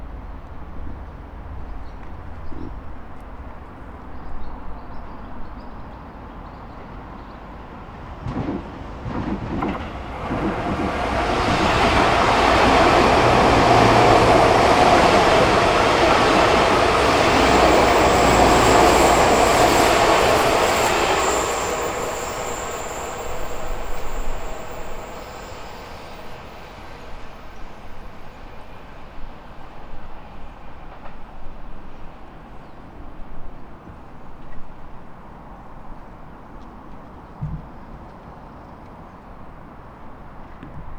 上り電車通過。
H5studio　内蔵マイク＋
ZOOM　ヘアリーウィンドスクリーン WSU-1